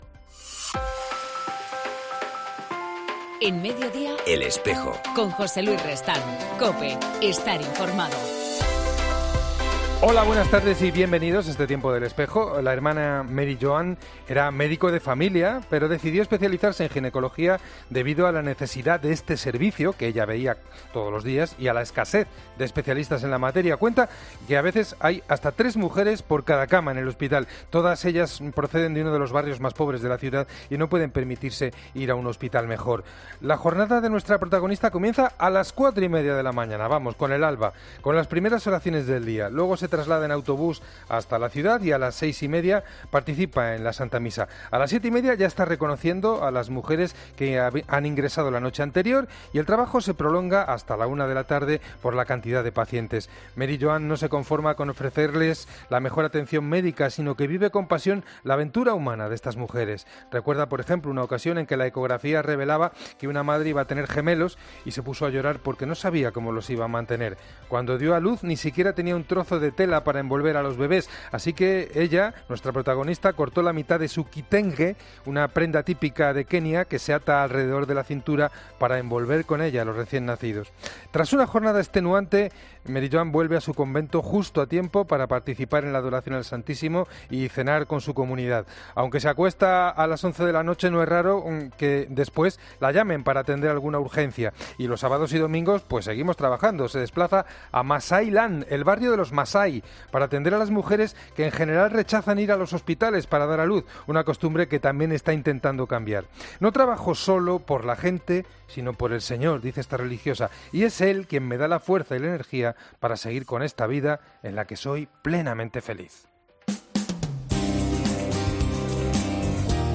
En El Espejo del 19 de junio entrevistamos al Arzobispo Octavio Ruíz Arenas